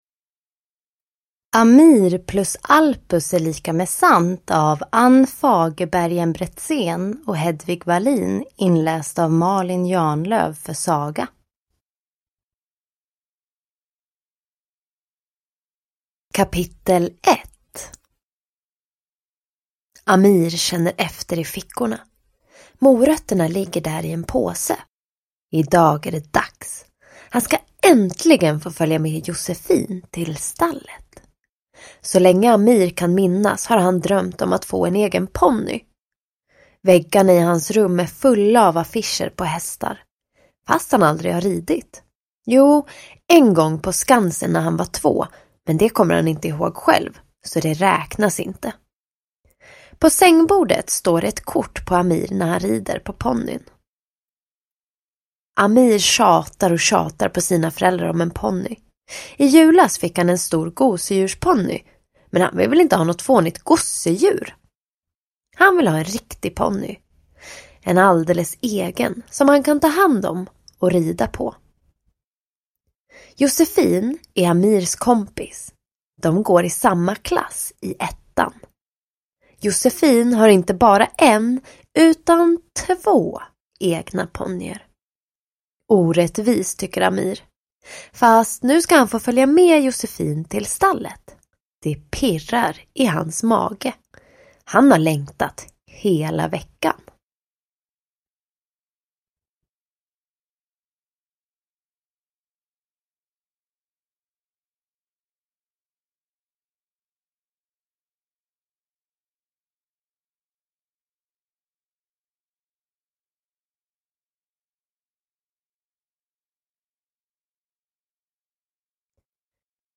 Hästar och ponnyer – Ljudbok